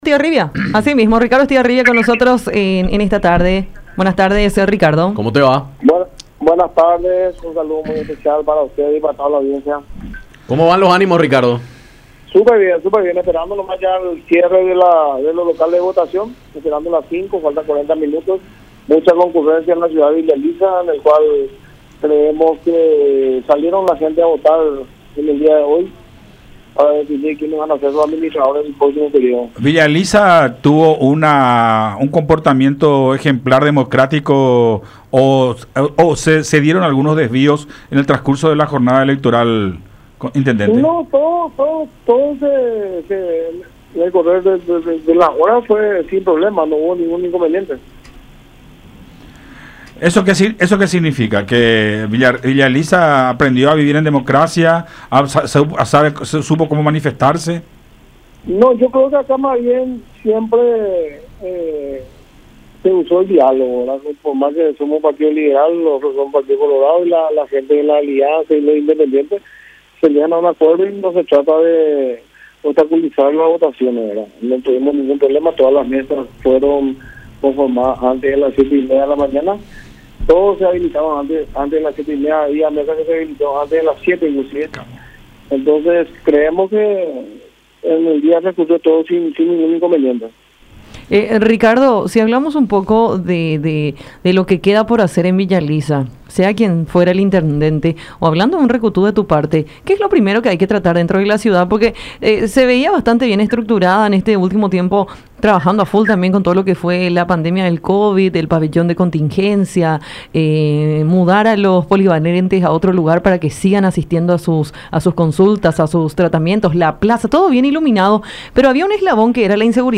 Creemos que en el día transcurrió todo sin ningún inconveniente”, dijo Estigarribia en conversación con La Unión.